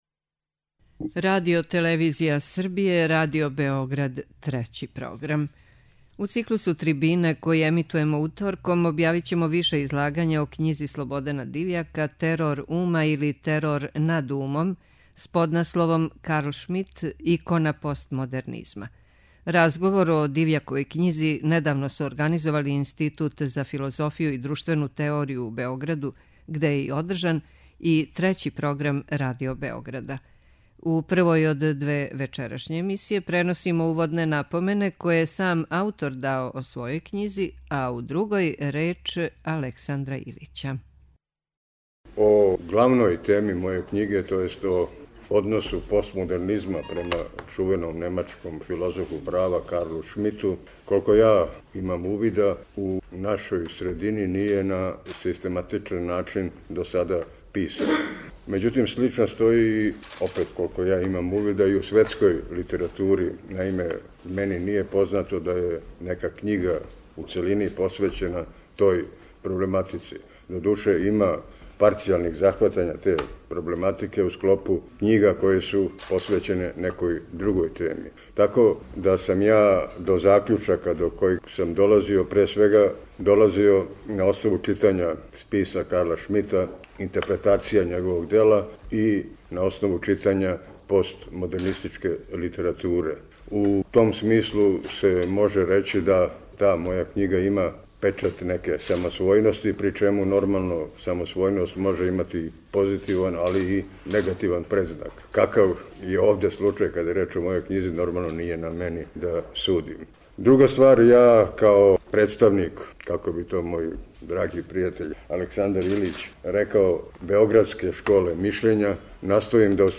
Трибине